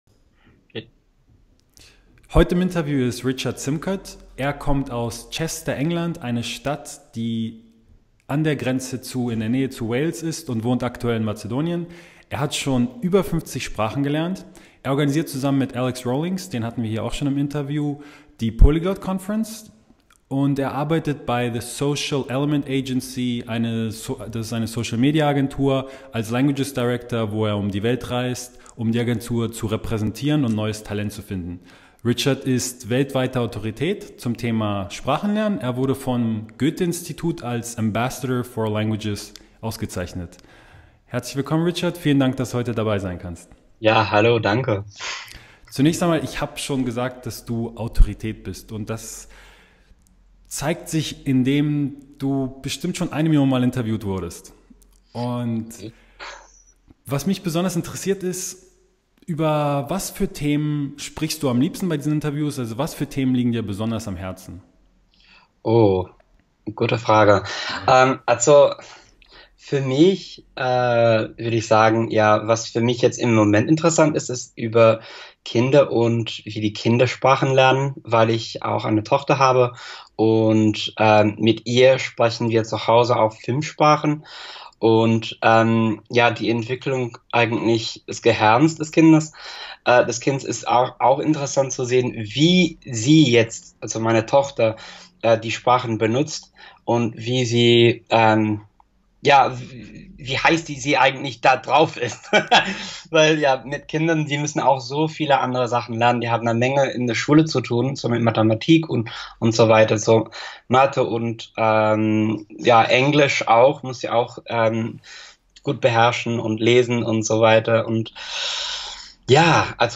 Im Interview mit mir teilt er seine besten Tipps, wie er es geschafft hat seine Tochter mehrsprachig zu erziehen und wie Du es auch bei deinem Kind schaffen kannst.